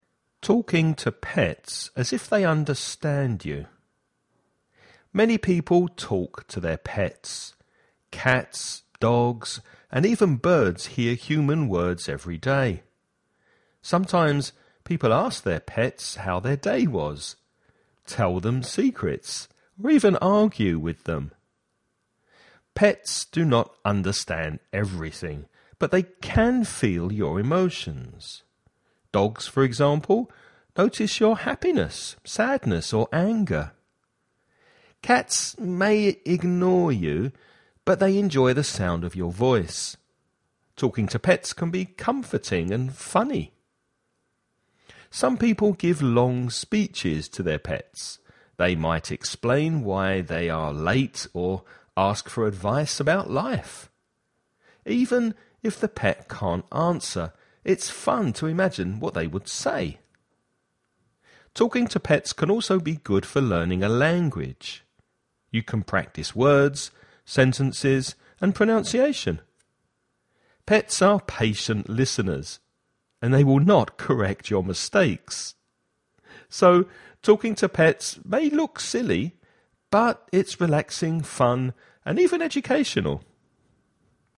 Listening Practice
You’re going to listen to a man talking about pets.